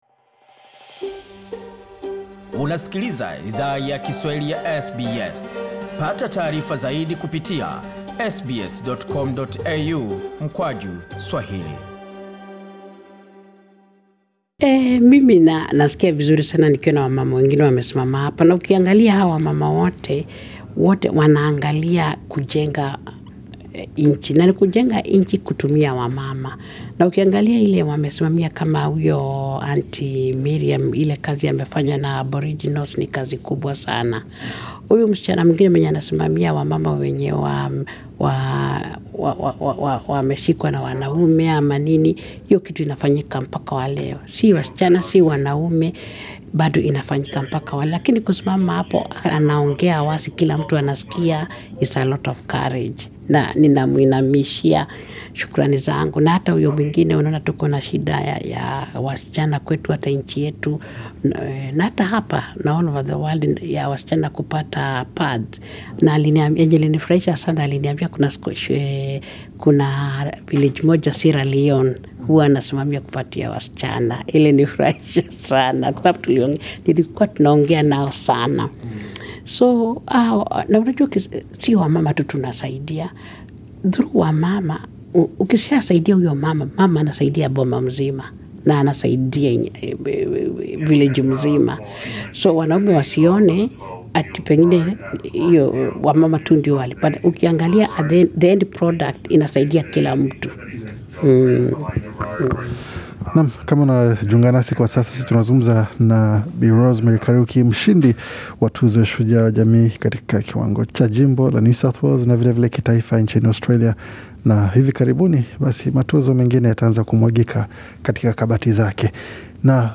Katika mazungumzo maalum na Idhaa ya Kiswahili ya SBS